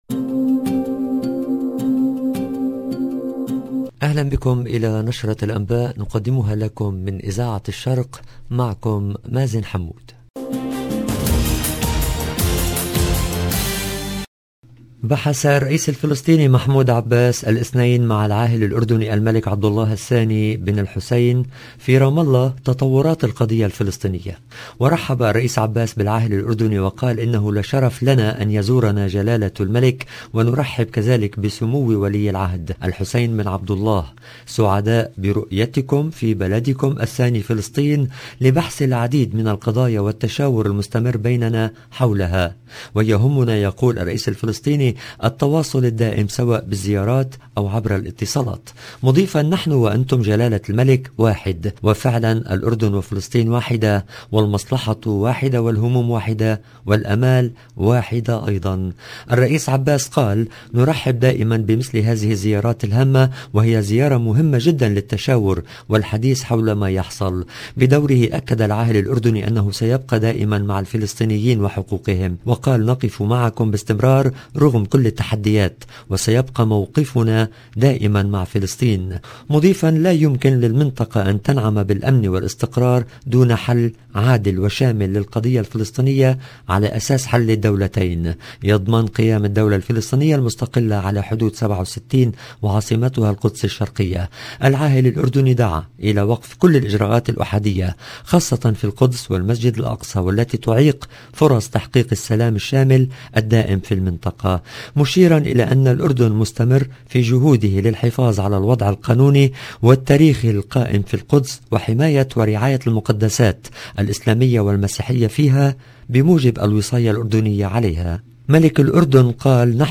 LE JOURNAL DU SOIR EN LANGUE ARABE DU 28/03/22